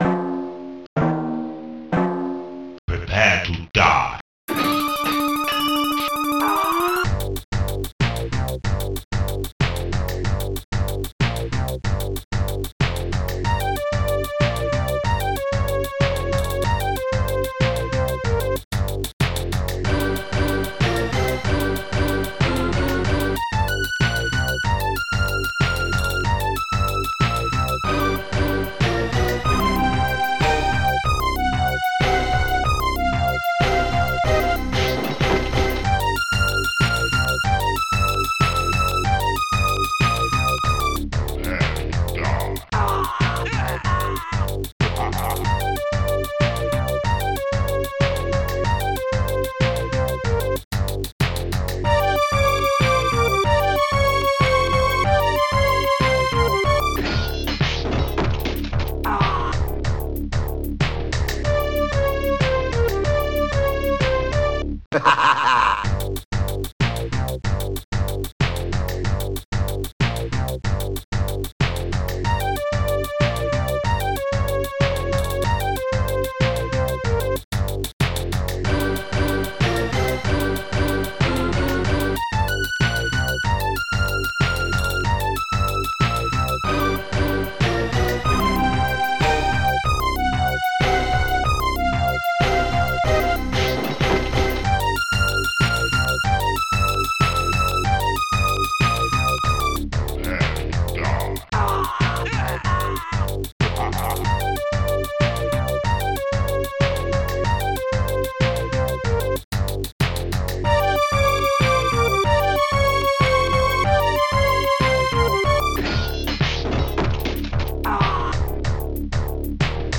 st-05:snare25
st-01:hihat2
st-06:bassdrum29
st-01:wowbass
st-05:buzzstring
st-01:strings3